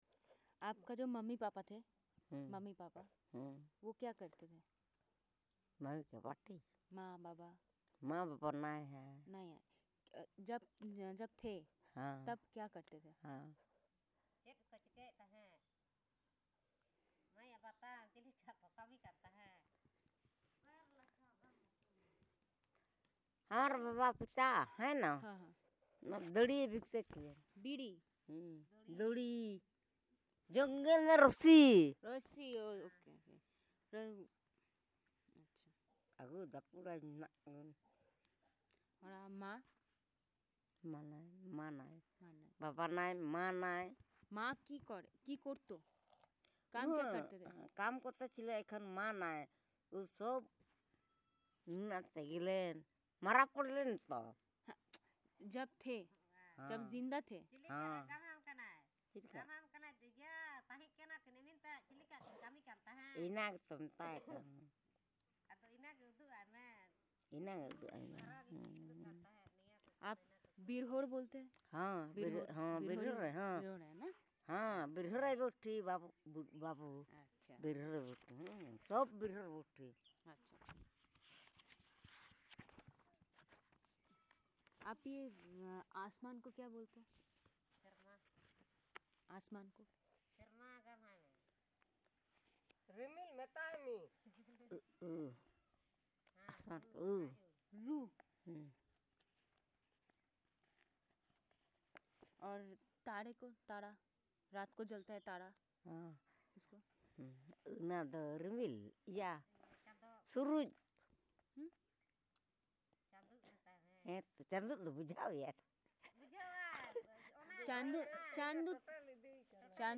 Elicitation of words related to natural objects, human body parts and related items